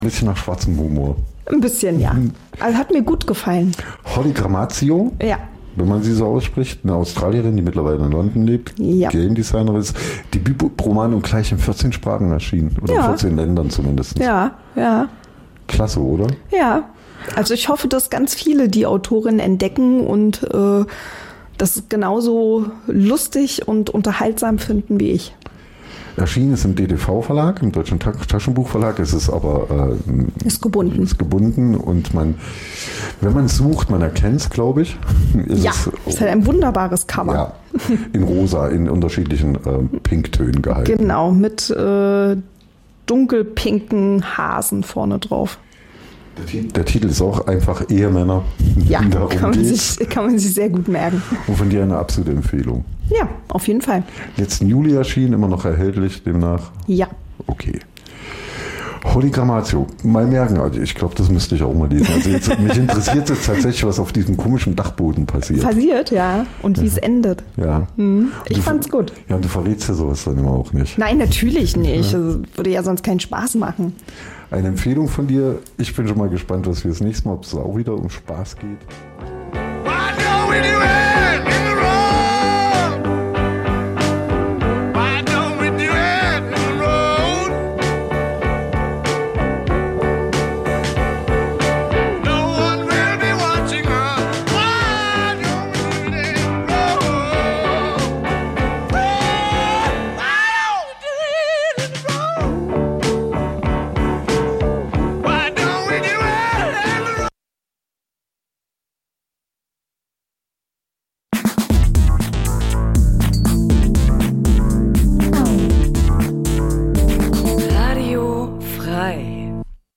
In �Unter Drei� widmen wir uns einmal im Monat dem Th�ringer Journalismus. In die einst�ndigen, thematischen Sendungen laden wir stets einen Gast ein, der oder die aus seinem/ihrem journalistischen Alltag im Freistaat erz�hlt. Dabei wollen wir nicht nur die Unterschiede der drei Gewerke Radio, Fernsehen, Zeitung/Online beleuchten, sondern auch einen Blick auf verschiedene Sender und Verlage werfen.